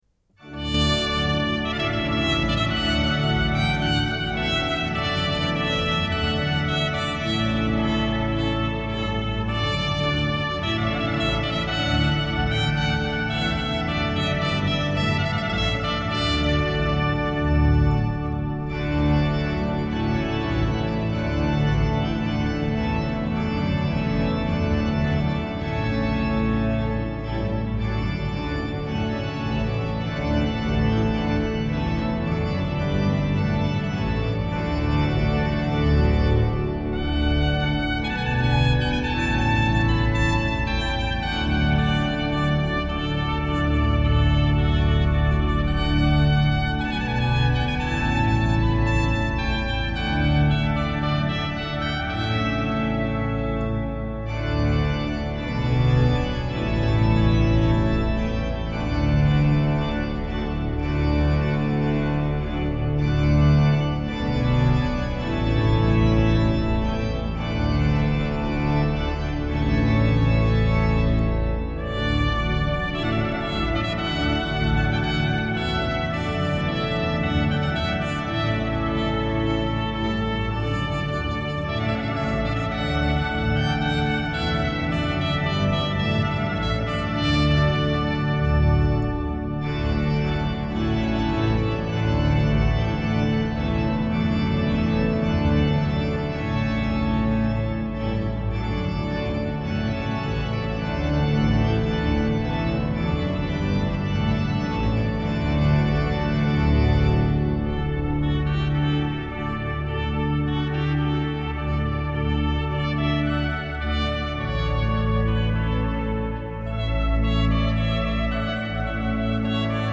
Concierto de Verano a la Luz de las Velas
al Órgano Allen de la S.I. Catedral Metropolitana de Valladolid.
with the sublime music from Clark for organ and trumpet.